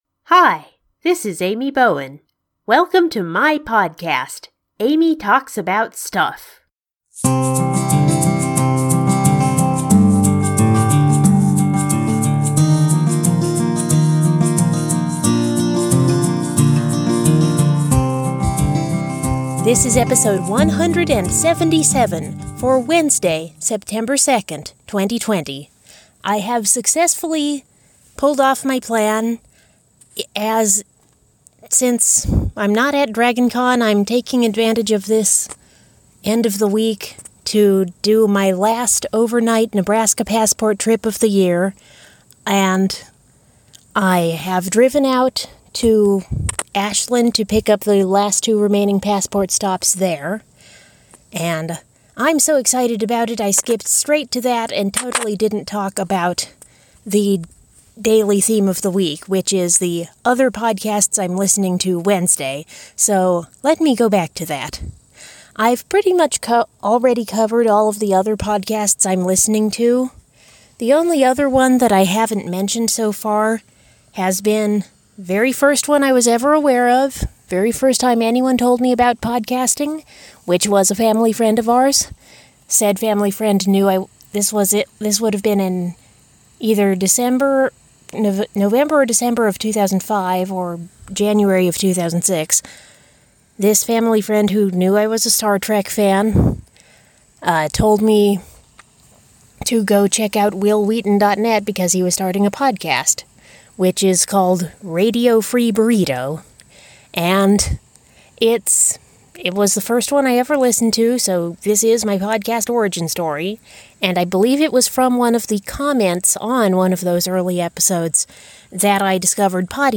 On ‘Other Podcasts I’m Listening To’ Wednesday, I share my podcasting origin story and more leftover Meta Monday content. Recorded on location in Ashland, Nebraska!